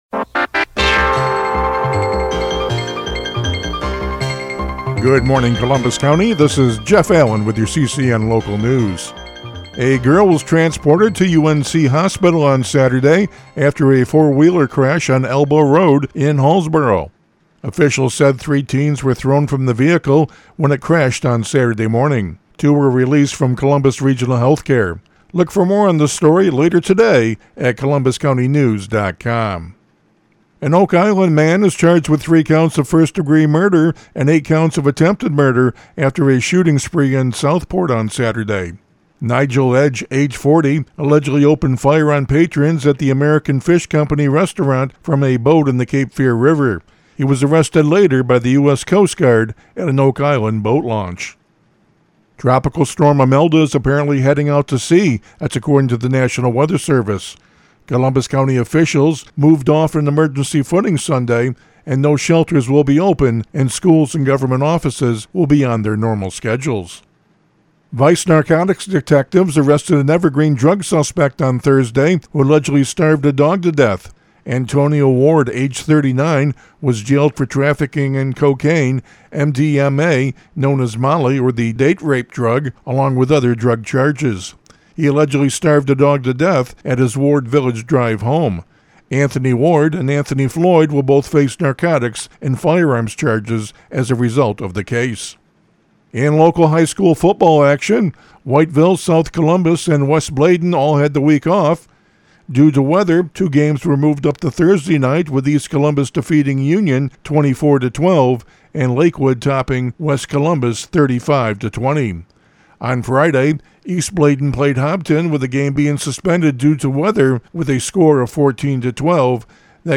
CCN Radio News — Morning Report for September 29, 2025
CCN-MORNING-NEWS.mp3